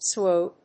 エスダブリューユー